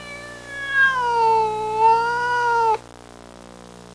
Cat Whine Sound Effect
Download a high-quality cat whine sound effect.
cat-whine.mp3